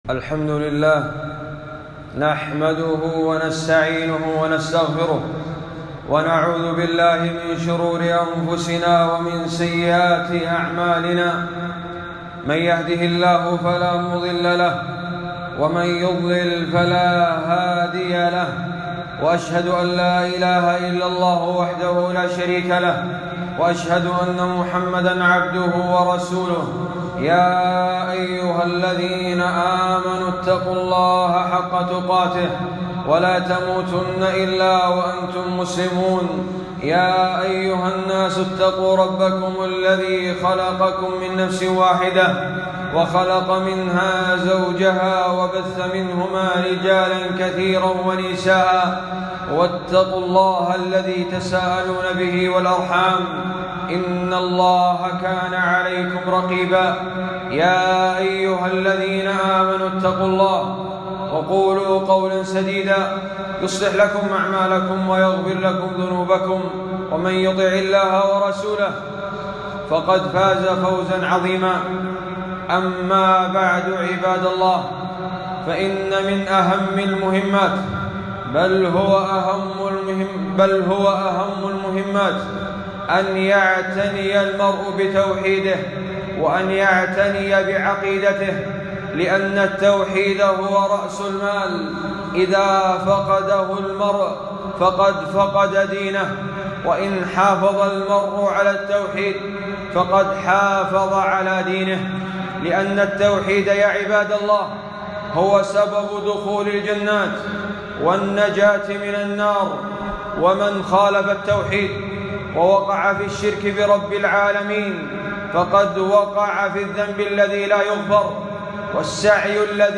خطبة - خطر الكهان وأحكام إتيانهم والحذر من الكهانة المعاصرة